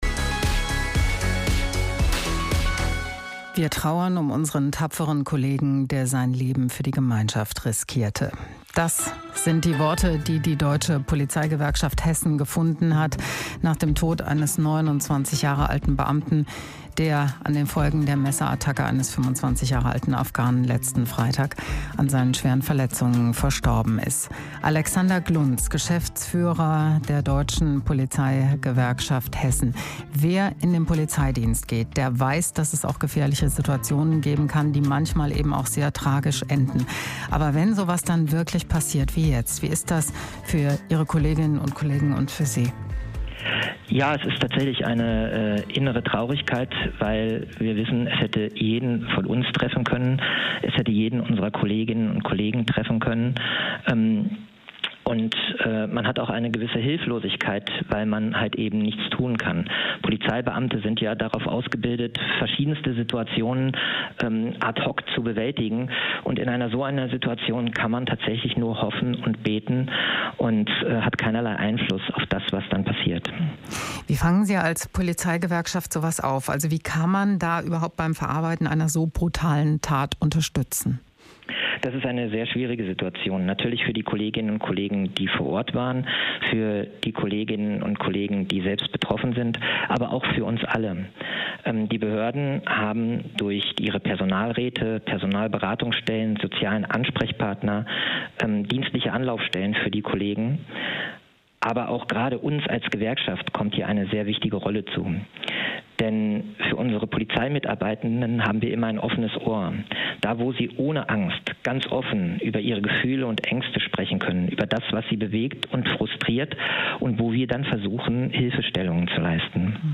HR1 Radiointerview